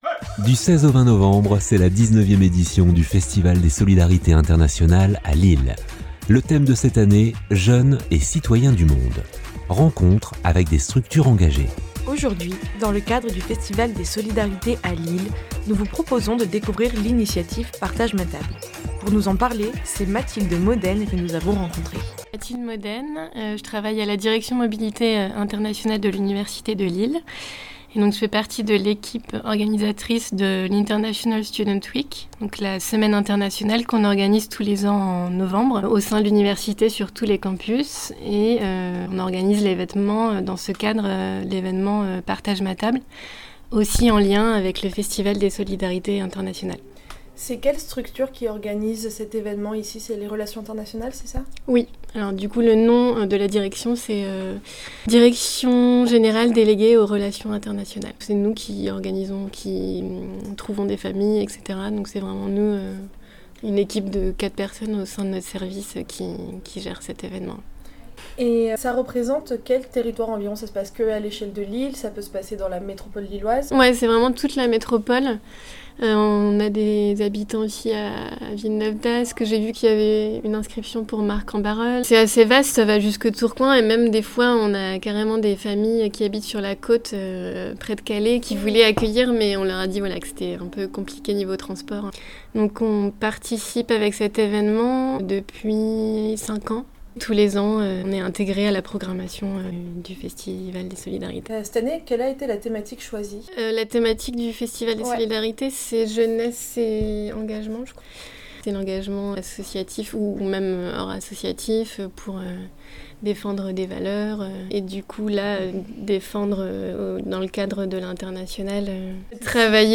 Podcast portrait avec l’Université de Lille et leur action « Partage ma table » :